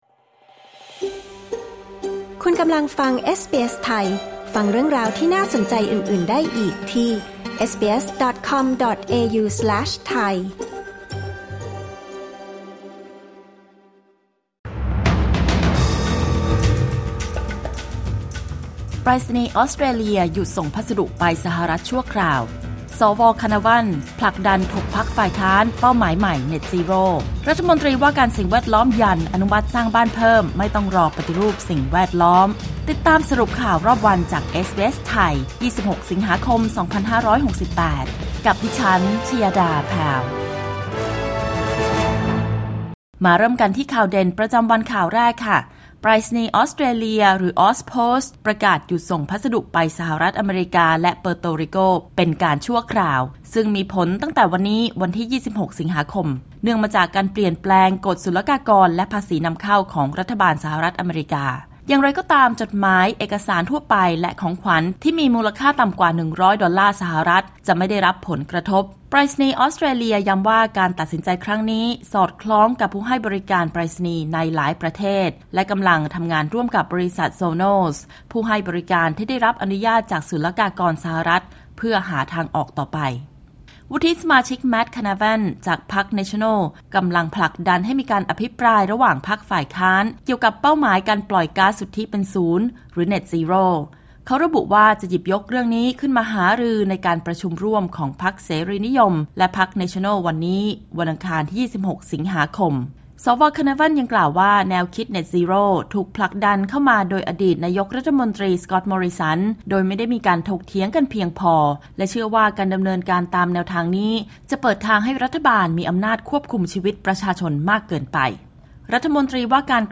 สรุปข่าวรอบวัน26 สิงหาคม 2568